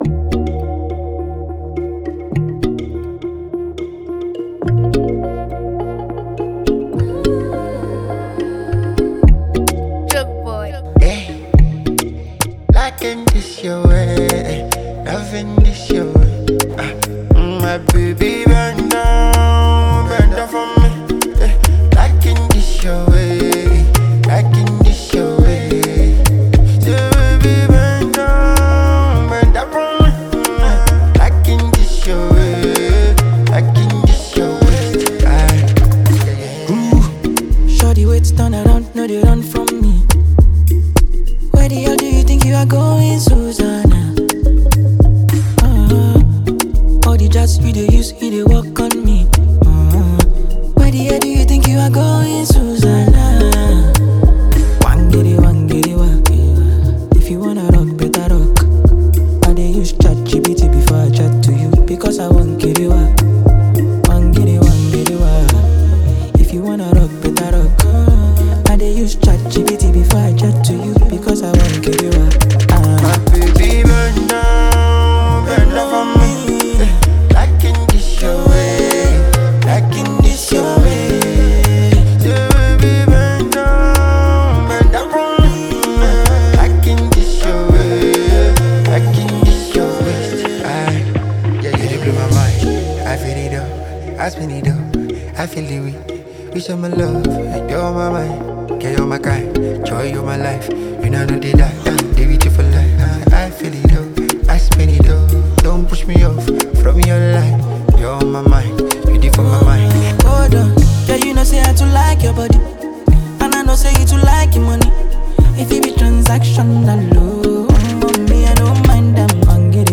” a high-energy track built for the dancefloor.
Driven by infectious rhythms and playful melodies